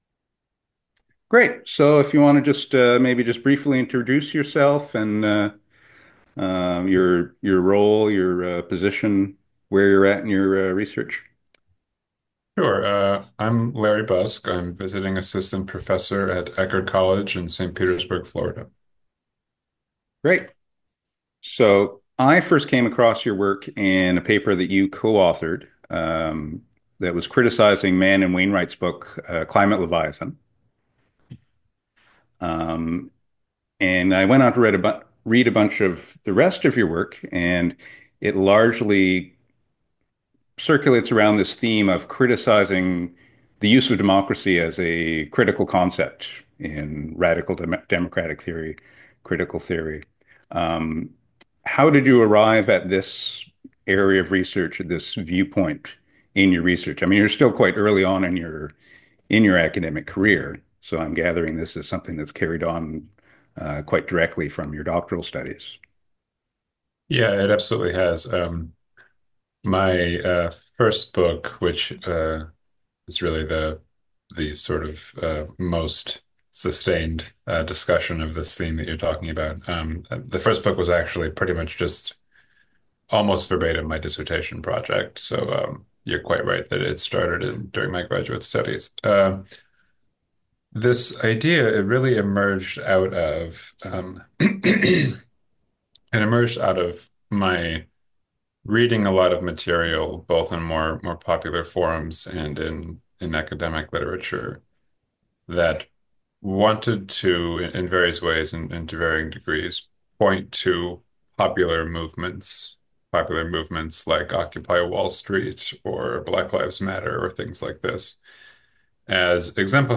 Democratic Dysfunction and Climate Catastrophe: A Conversation With Political Philosopher